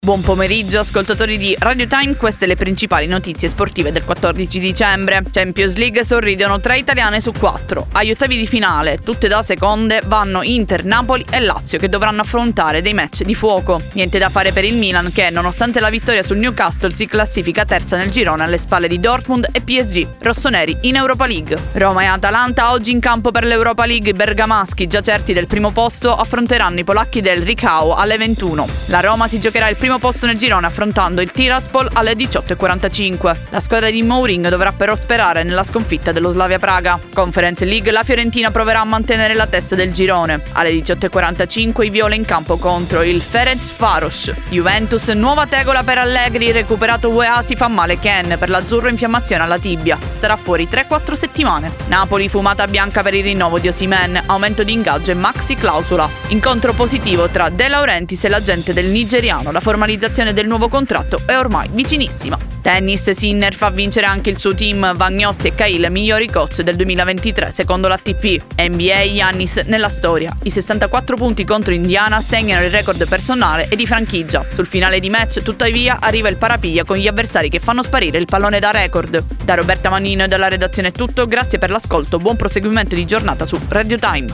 notiziario-14-dicembre.mp3